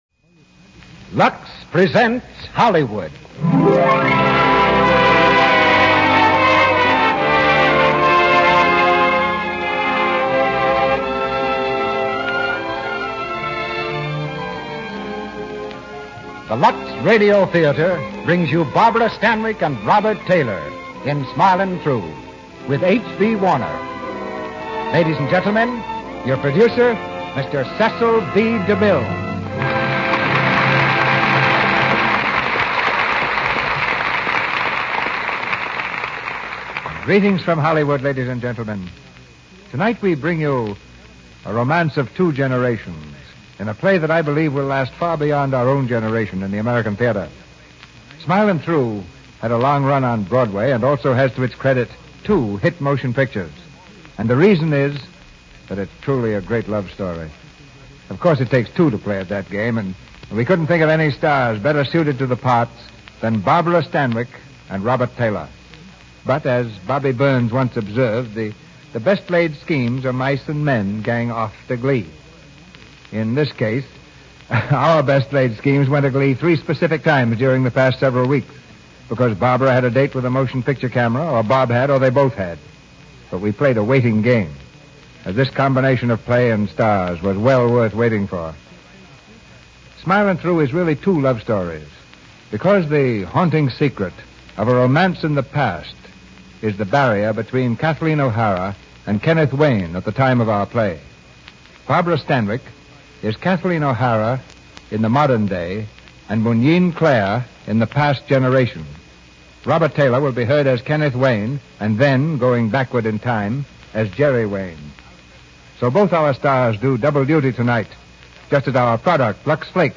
Lux Radio Theater Radio Show